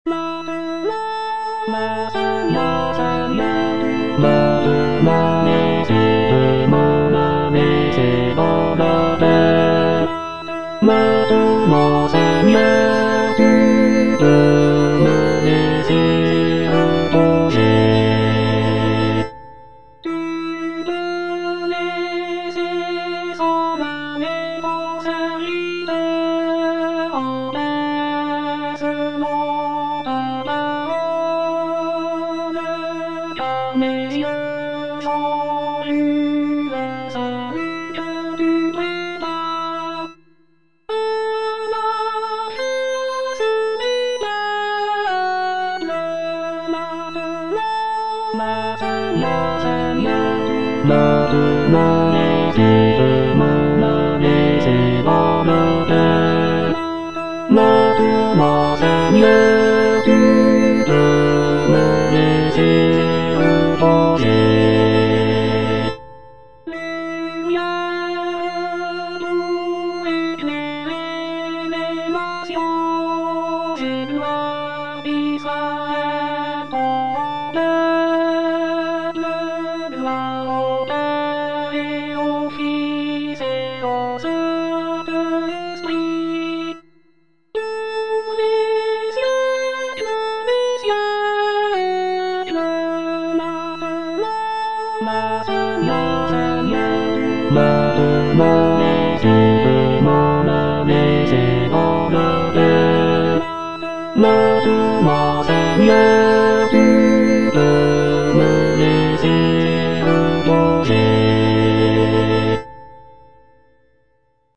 Bass (Emphasised voice and other voices)
choral composition